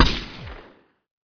枪声 " 枪声 2 激光
描述：枪炮射击的混合声音。这种声音感觉是"激光"
Tag: 激光 混音 拍摄